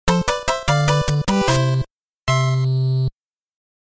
applause-lick.mp3